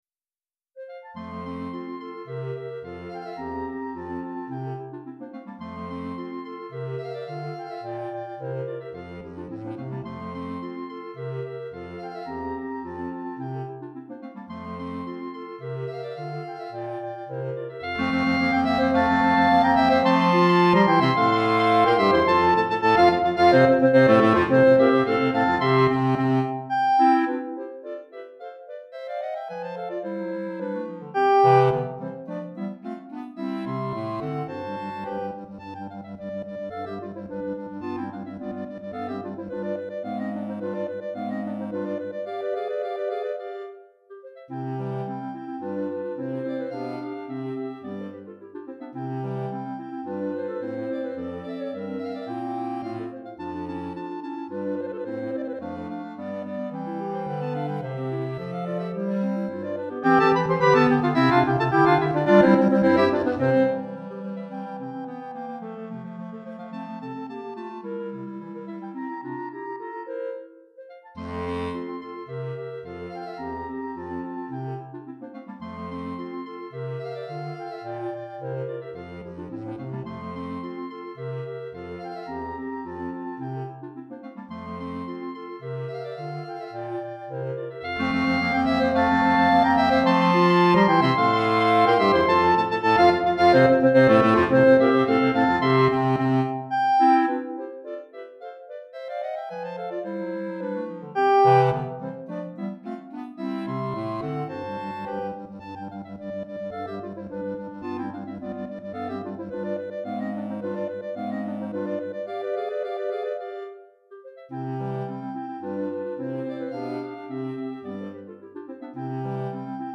4 Clarinettes en Sib et Clarinette Basse